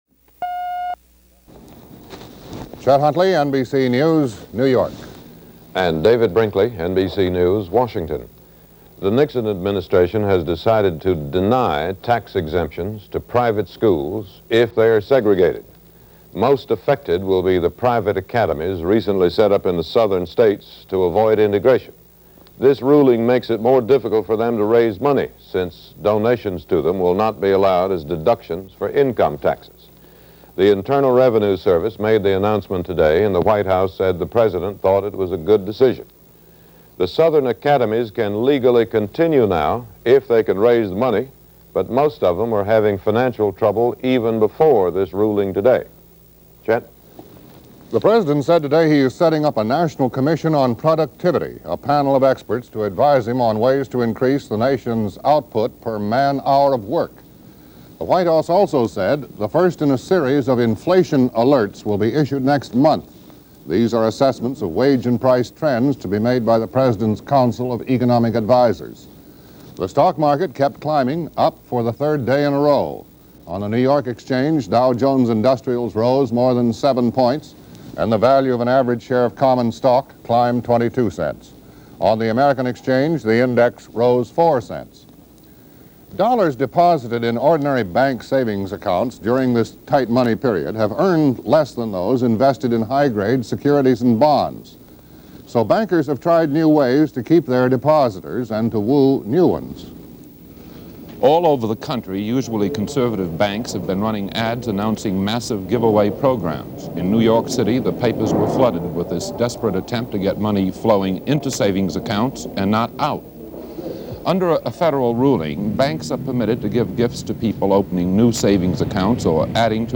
– NBC Nightly News With Chet Huntley and David Brinkley – July 10, 1970 –